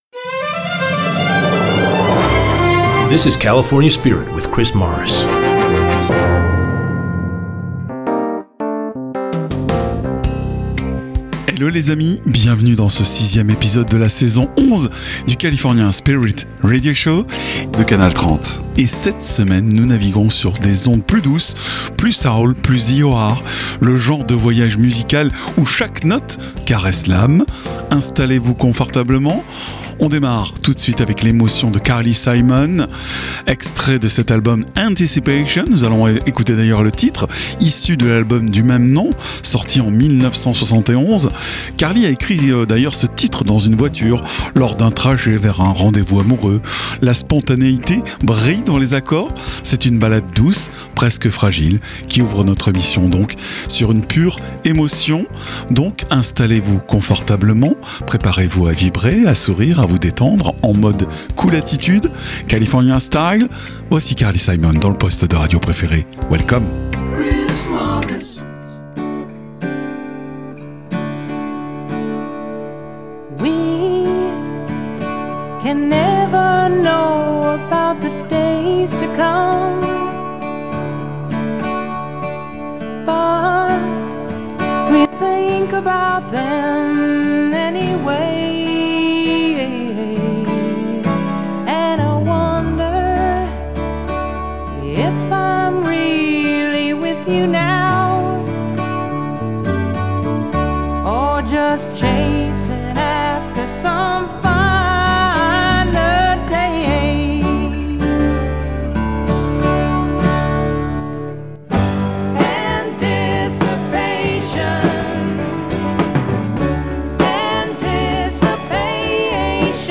Le California Spirit est un concept unique inspiré des radios US , avec des PowersPlays (nouveautés) et ExtraGold (Oldies).
C’est un format musique californienne (Allant du Classic Rock en passant par le Folk, Jazz Rock, Smooth jazz) le tout avec un habillage visuel très 70’s et un habillage sonore Made in America.